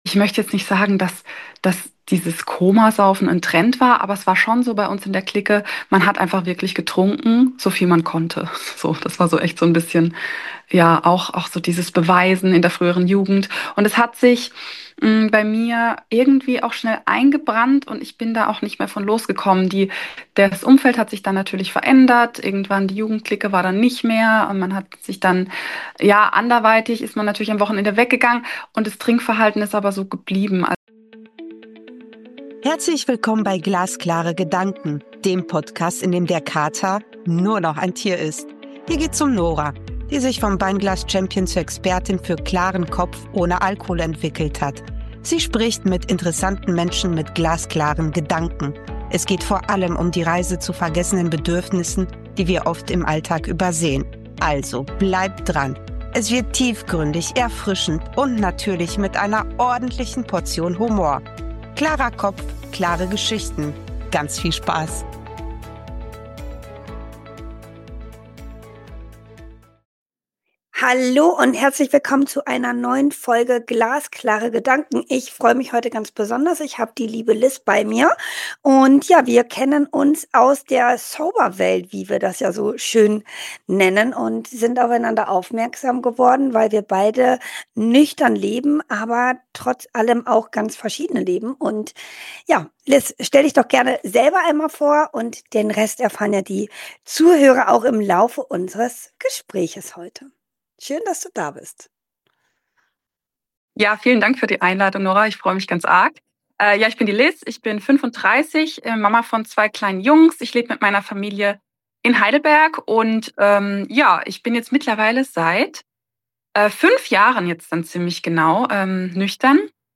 Ehrlich.